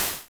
SI2 NOIZE 0F.wav